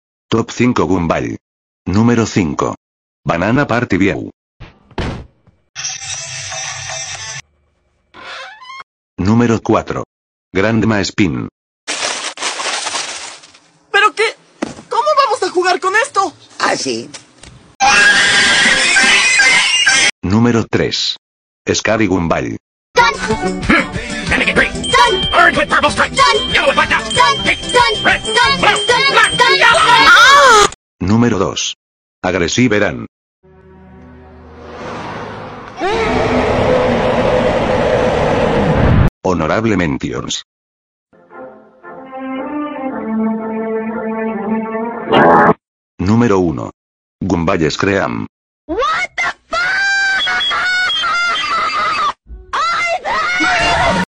Top 5 Gumball sound effects free download